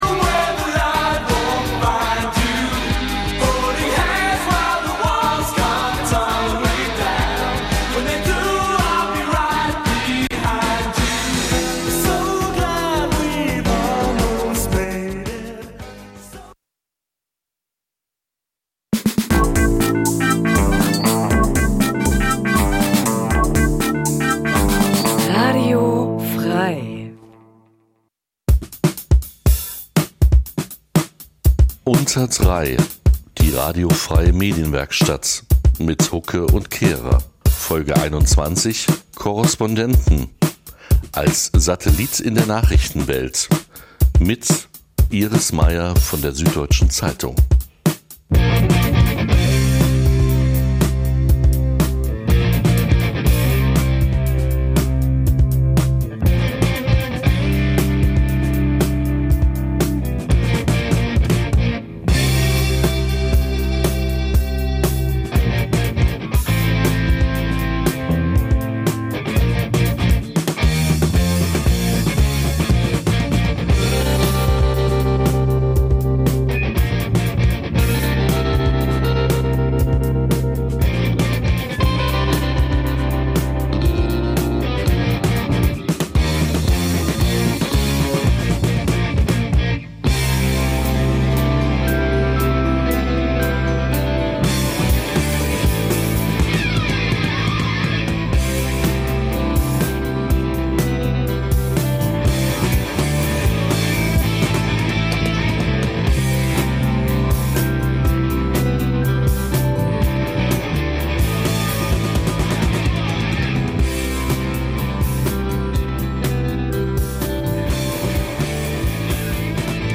In �Unter Drei� widmen wir uns einmal im Monat dem Th�ringer Journalismus. In die einst�ndigen, thematischen Sendungen laden wir stets einen Gast ein, der oder die aus seinem/ihrem journalistischen Alltag im Freistaat erz�hlt.